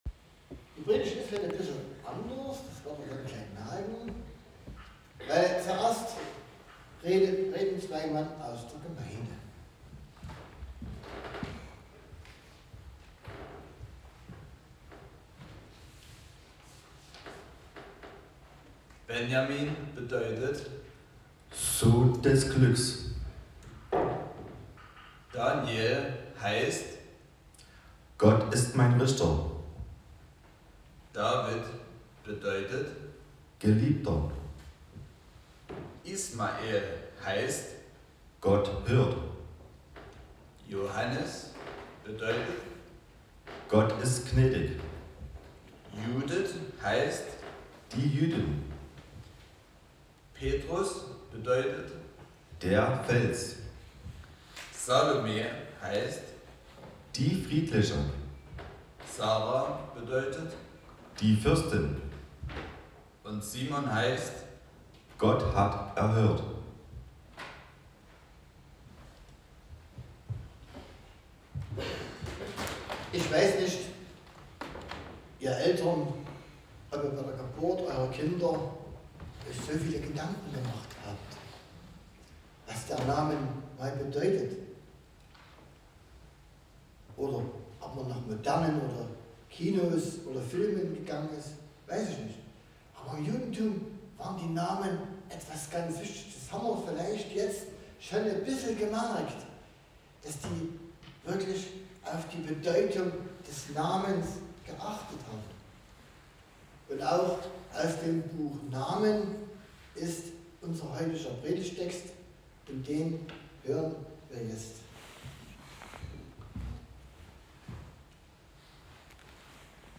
Passage: 2.Mose 33; 17b-33 Gottesdienstart: Predigtgottesdienst Wildenau « Die Taufe verändert alles!